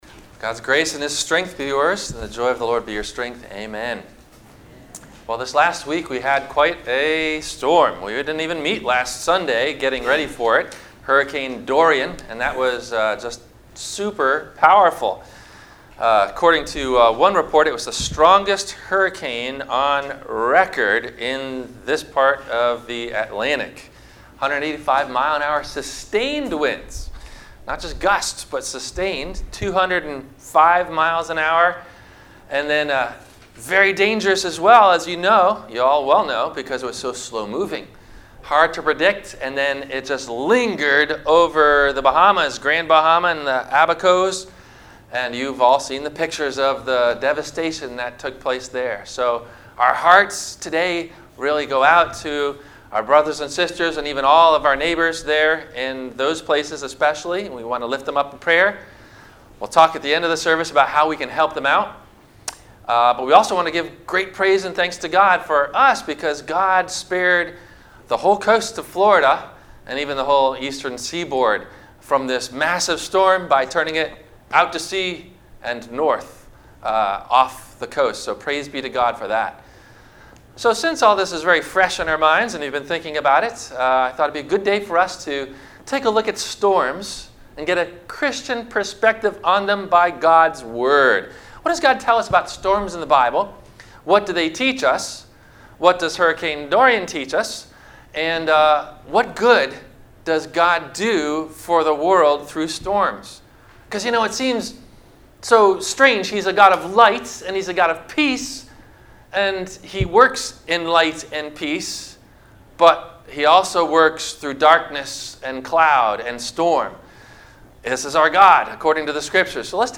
God, Our Shelter From The Storm - Sermon - September 08 2019 - Christ Lutheran Cape Canaveral